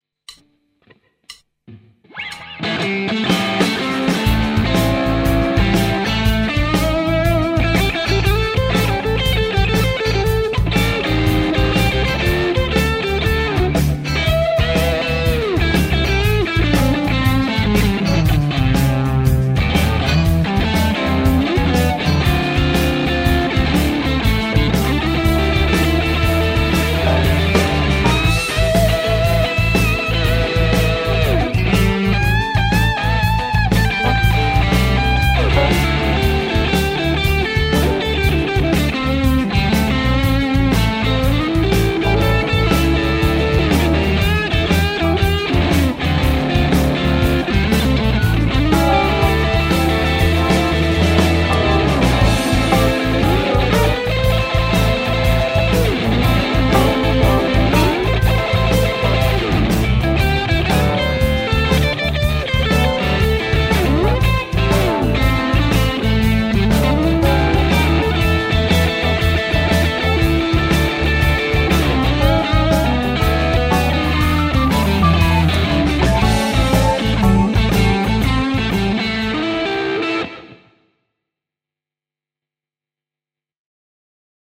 Tollasta shufflea 3 kieppiä, kierrätystausta jostain 9 vuoden takaa, ei liene ollut sen jälkeen..?